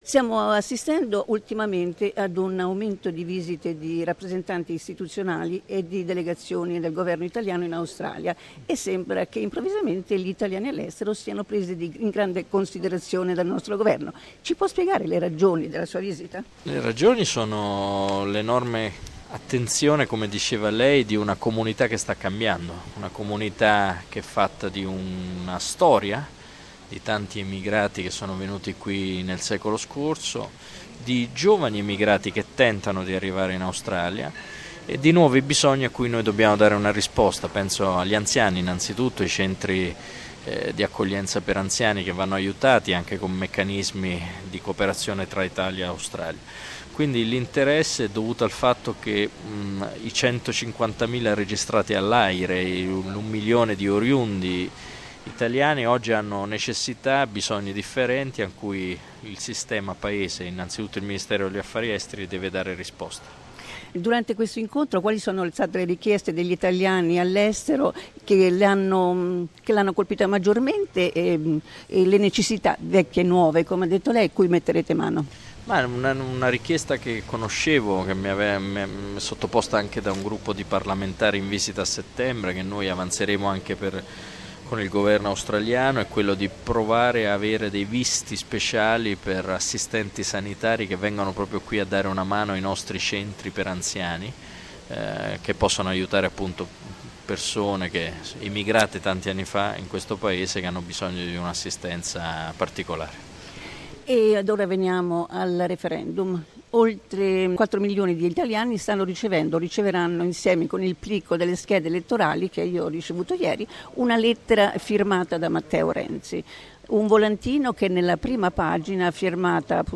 We asked Democratic Party undersecretary for Foreign Affairs and International Cooperation, Vincenzo Amendola, what was the purpose of his recent visit to Australia. In particular, we talked about the possible outcome of the forthcoming referendum which will take place in Italy on the 4th of December.